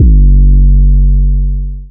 DDW3 808 2.wav